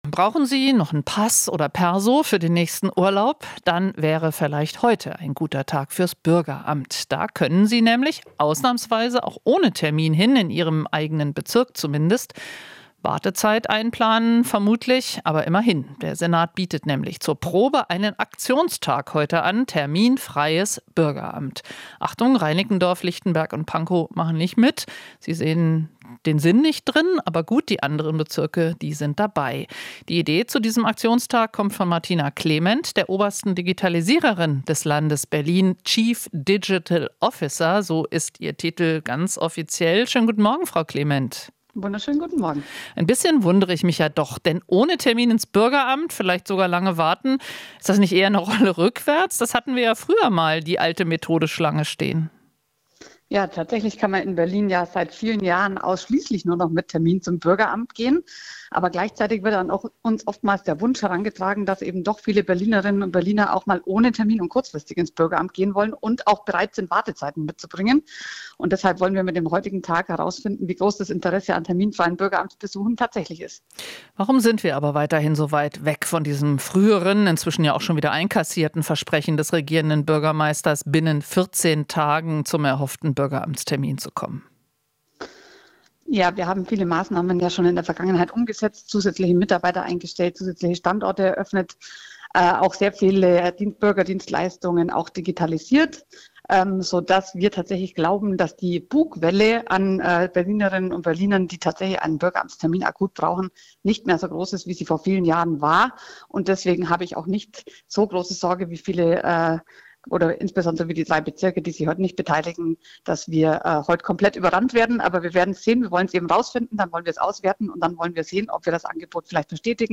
Interview - Terminfreier Tag bei Berliner Bürgerämtern
Berlin testet am Mittwoch terminfreie Zeiten in den meisten Bürgerämtern. Mit dem Aktionstag wolle man untersuchen, wie groß das Interesse an terminfreien Bürgeramtsbesuchen tatsächlich ist, sagt die Staatssekretärin für Digitalisierung, Martina Klement (CSU).